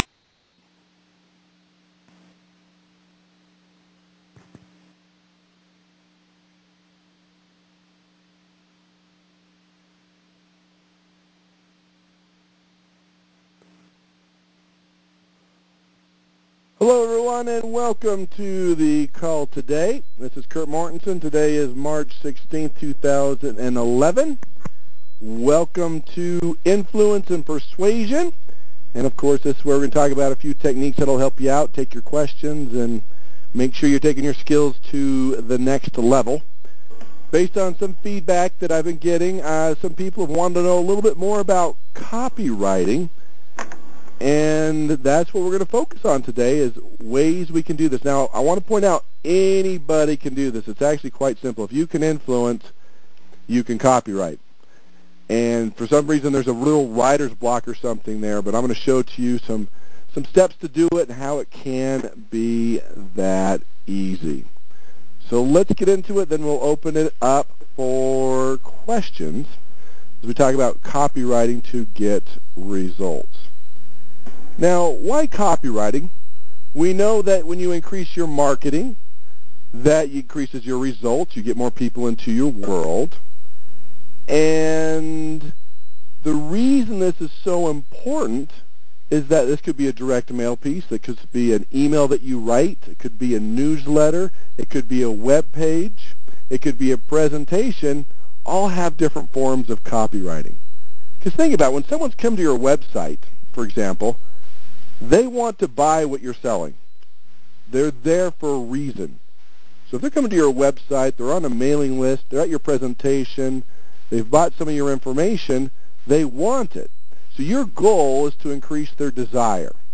‹ Confused Mind Says No Core Elements of Influence › Posted in Conference Calls